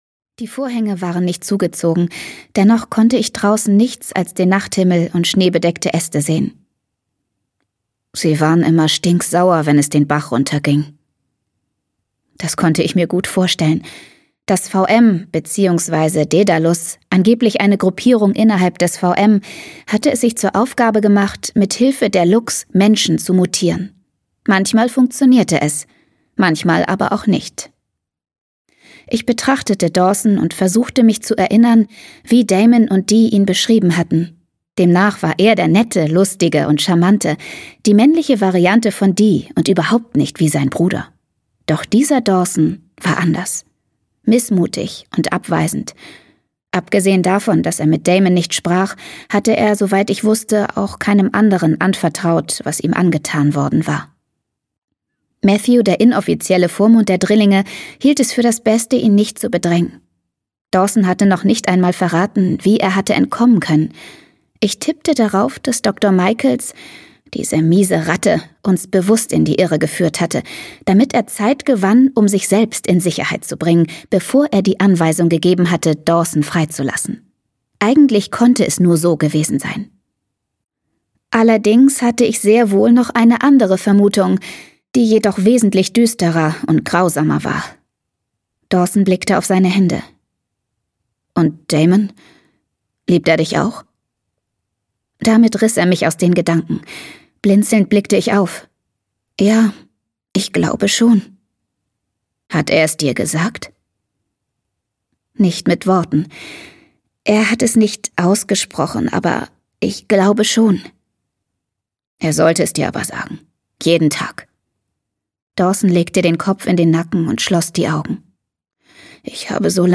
Obsidian 3: Opal. Schattenglanz - Jennifer L. Armentrout - Hörbuch